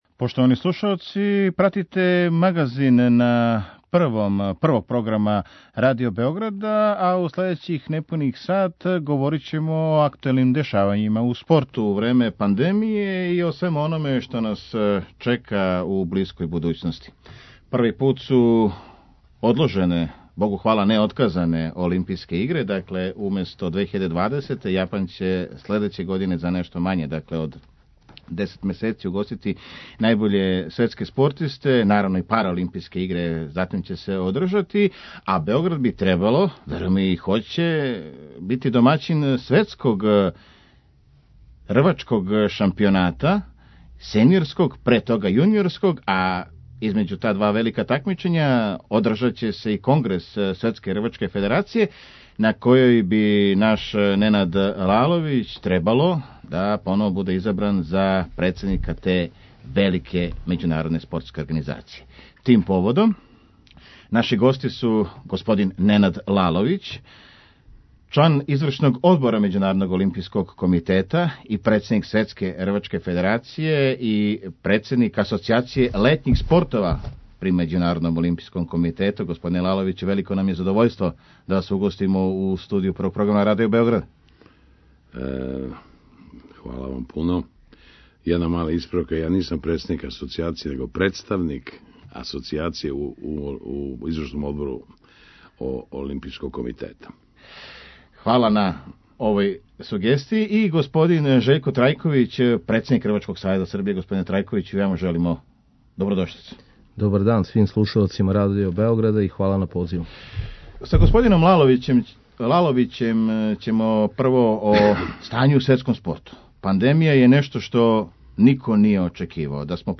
Гости Недељног магазина на Првом су члан Извршног одбора Међународног олимпијског комитета и председник Светске рвачке федерације Ненад Лаловић